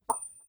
menu-direct-click.wav